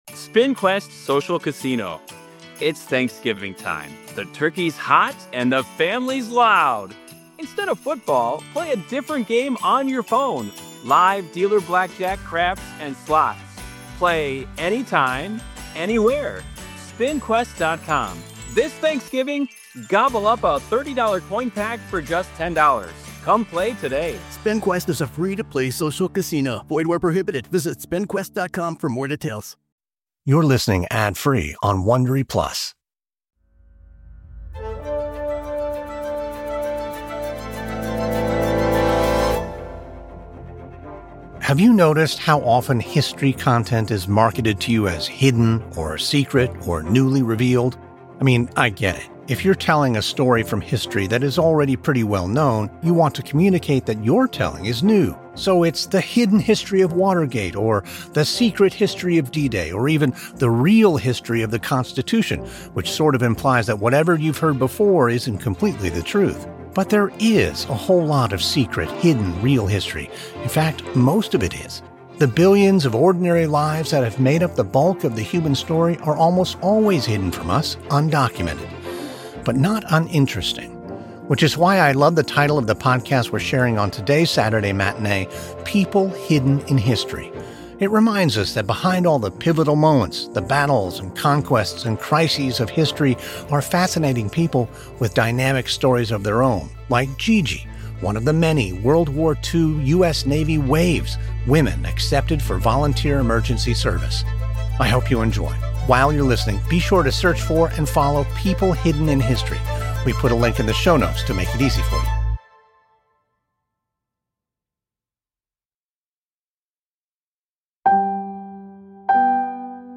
On today’s Saturday Matinee, we hear one woman's story about her experience as a WWII Navy WAVES- Women Accepted for Volunteer Emergency Services.